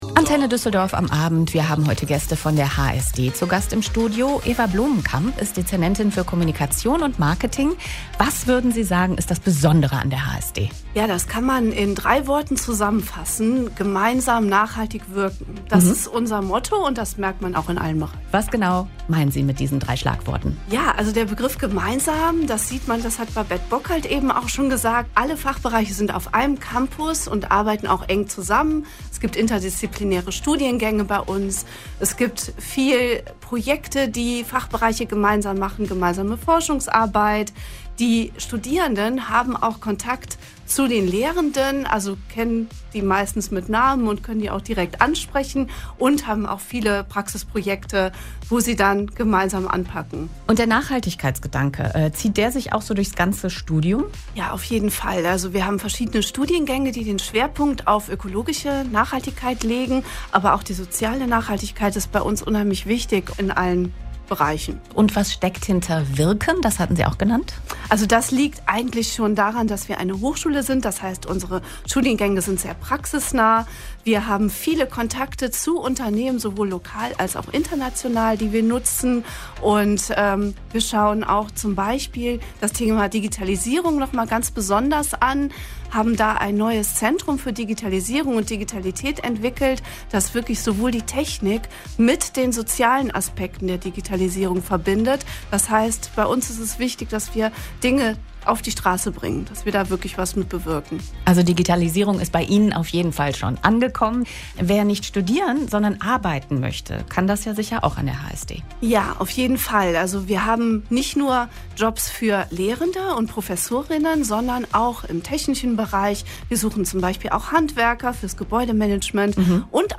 Das HSD-Team war am 12.06.2025 bei Antenne Düsseldorf zu Gast und hat einiges über den Tag der offenen Tür erzählt.